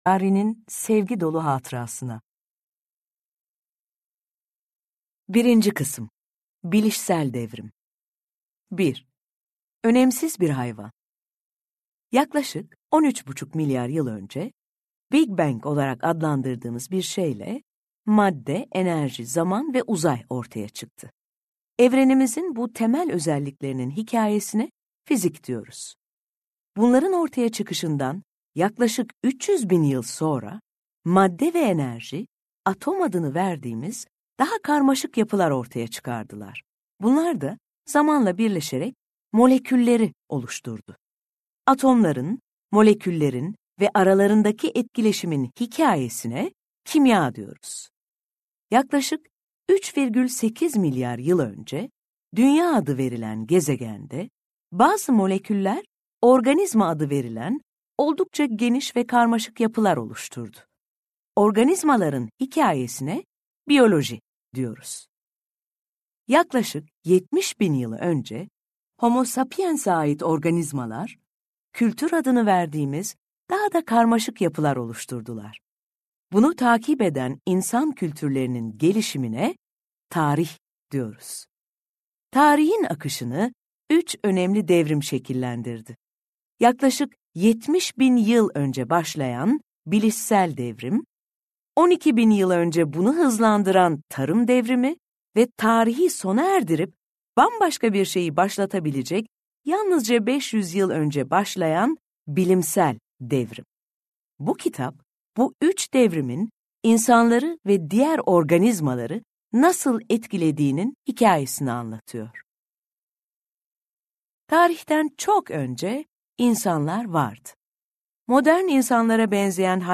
Sapiens - Seslenen Kitap
Hayvanlardan Tanrılara – Sapiens’i Tilbe Saran’ın seslendirmesiyle dinleyebilirsiniz.